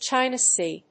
アクセントChína Séa